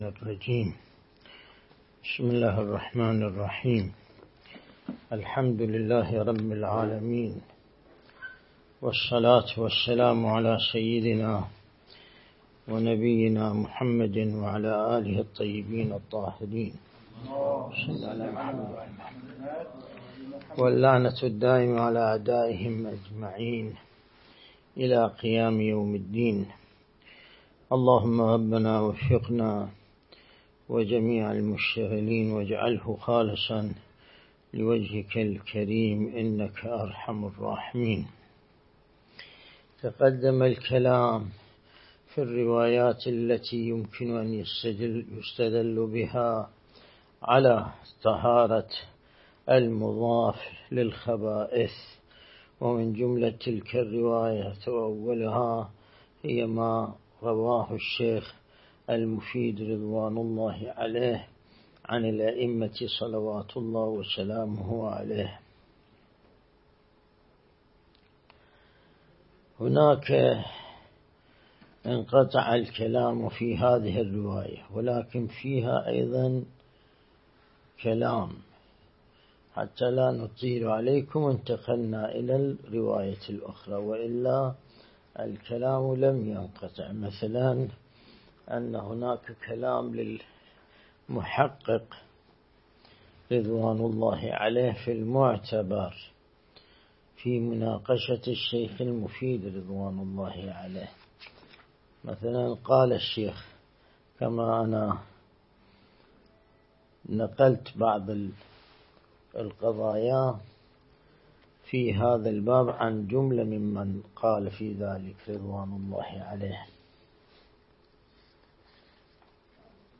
بحث خارج الفقه - كتاب الطهارة(56)
الدرس الاستدلالي شرح بحث الطهارة من كتاب العروة الوثقى